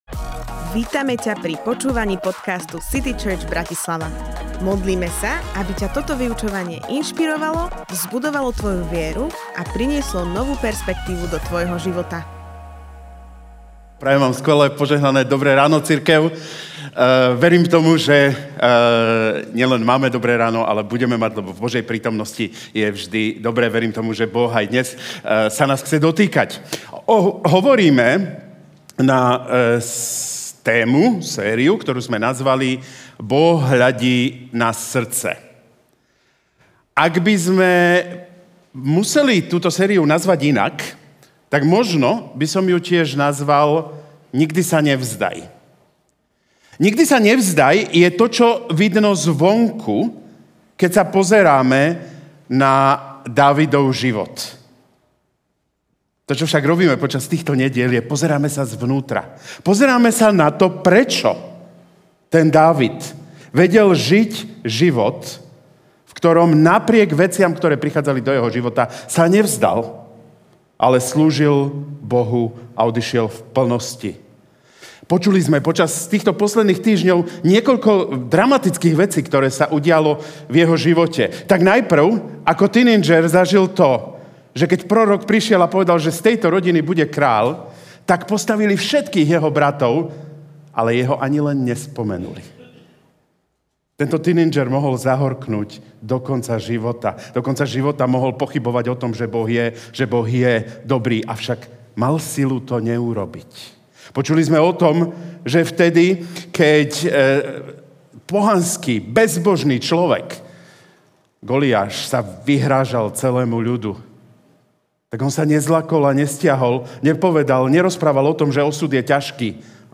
Priprav cestu ďalším Kázeň týždňa Zo série kázní